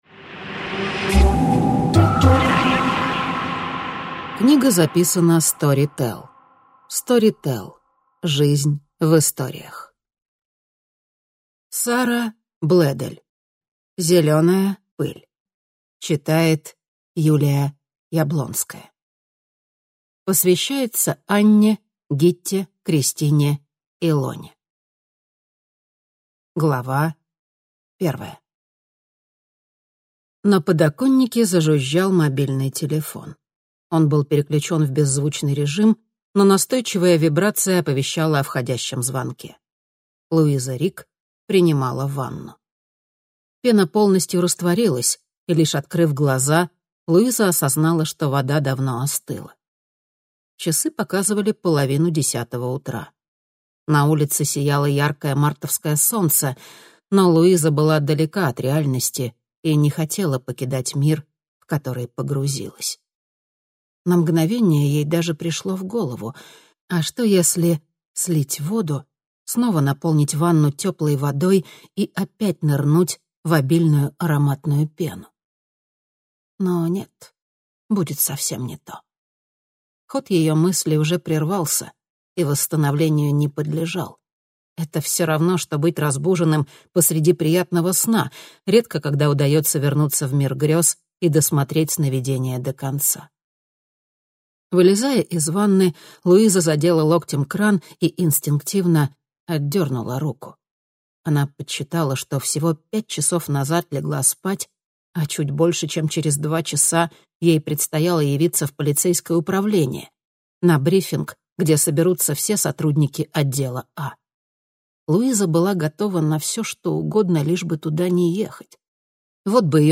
Аудиокнига Зеленая пыль | Библиотека аудиокниг